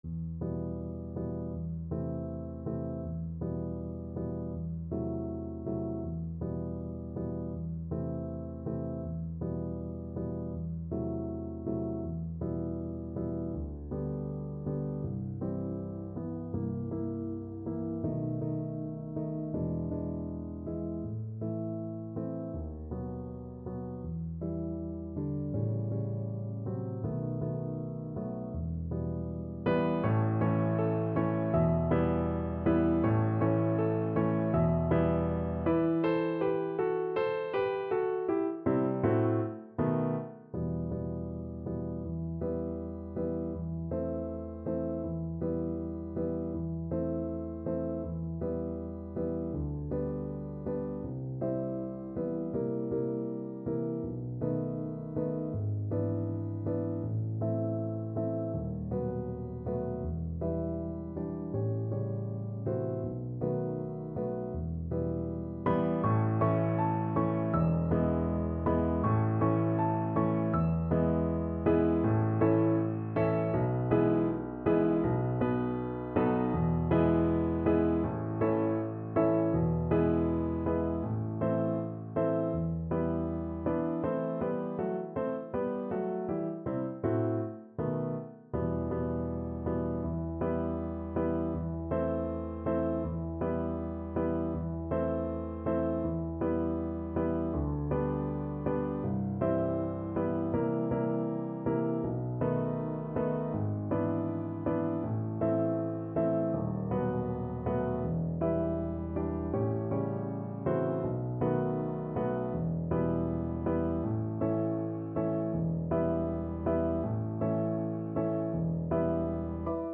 ~ = 100 Andante
Classical (View more Classical Cello Music)